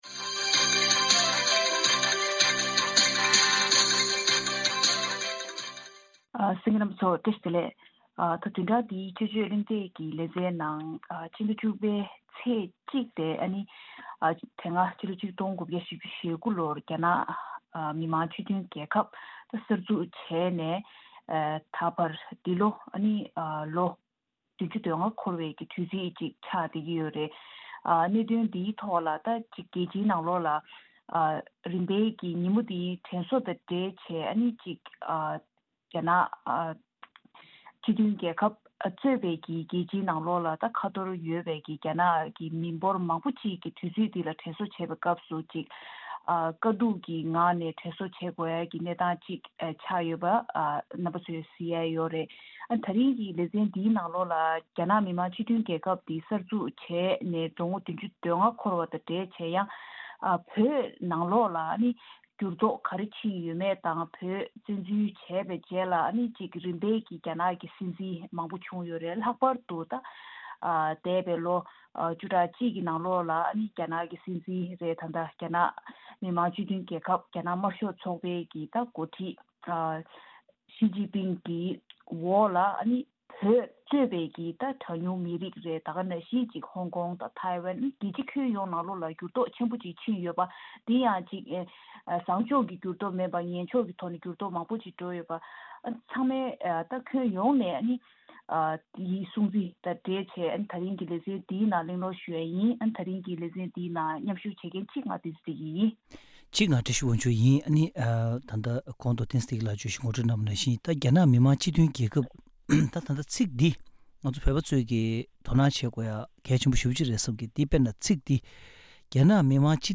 བདུན་རེའི་དཔྱད་བརྗོད་གླེང་སྟེགས